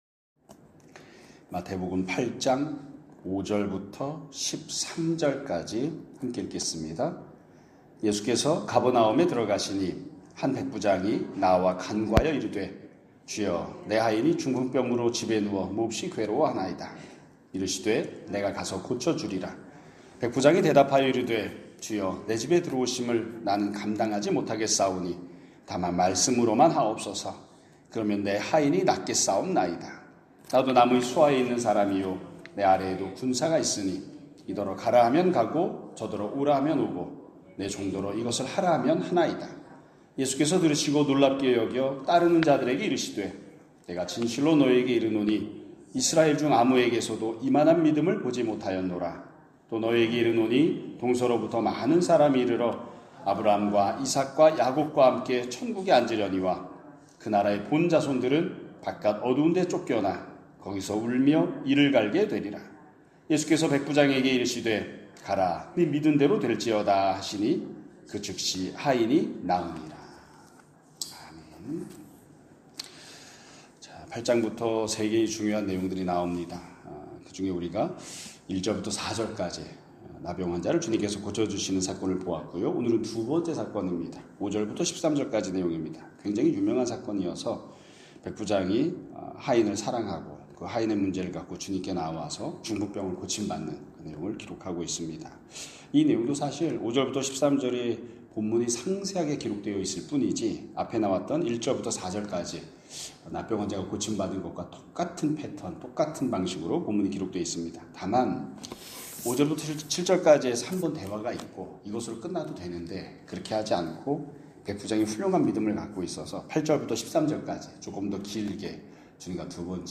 2025년 7월 9일(수요 일) <아침예배> 설교입니다.